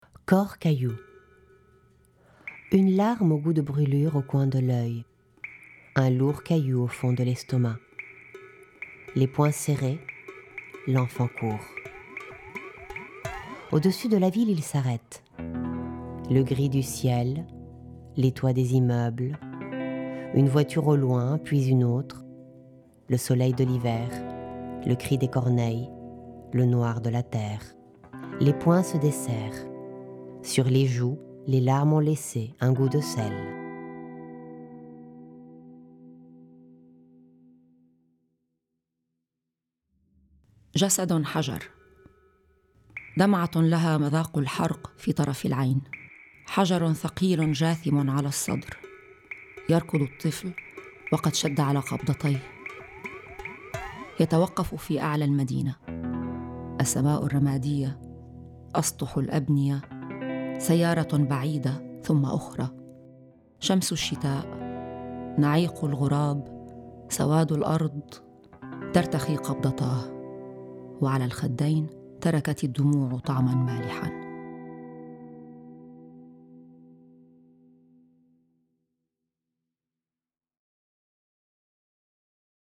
Plage-3-Corps-caillou_bilingue.mp3